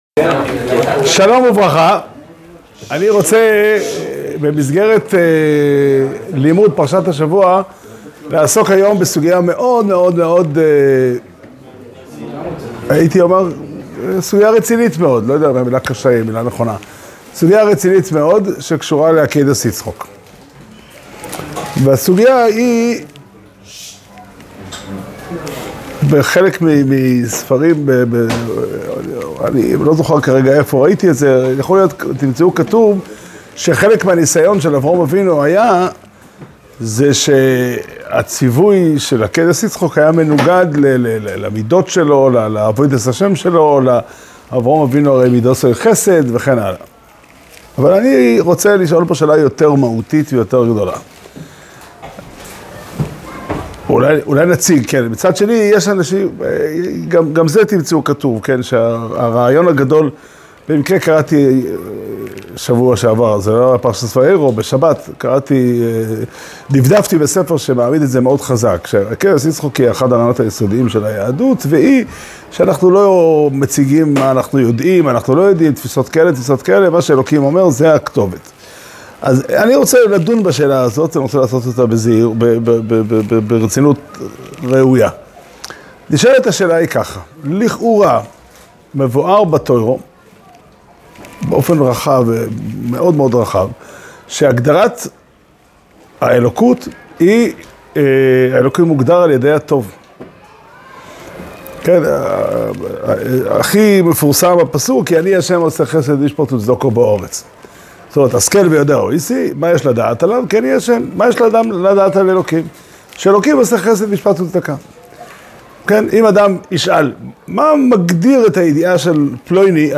שיעור שנמסר בבית המדרש פתחי עולם בתאריך י' חשוון תשפ"ה